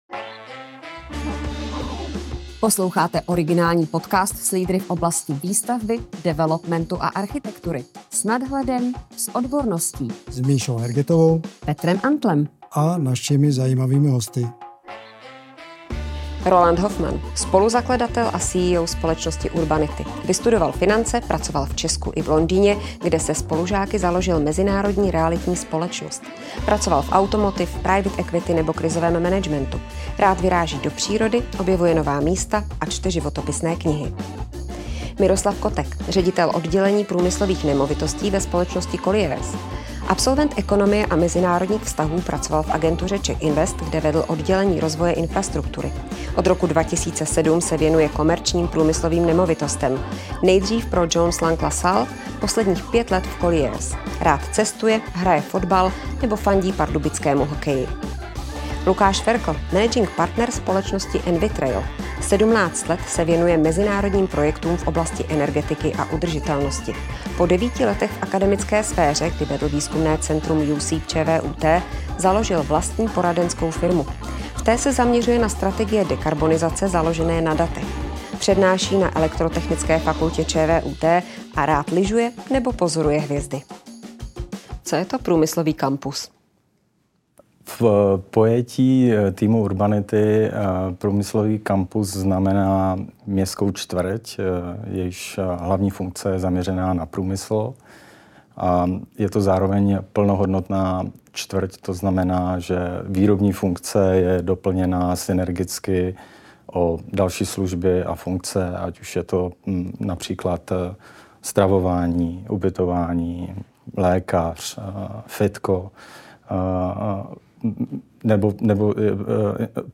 Diskuze: Opuštěné brownfieldy mohou někdy sloužit výrobě dál.